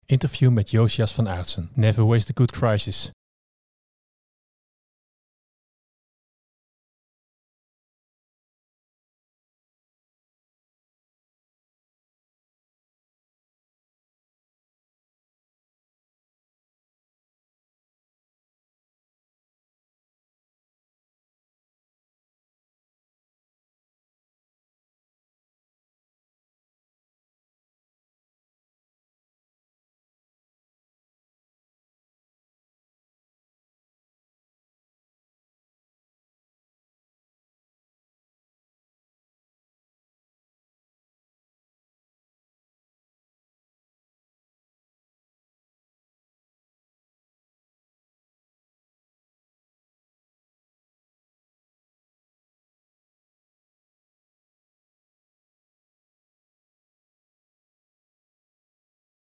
Interview met Jozias van Aartsen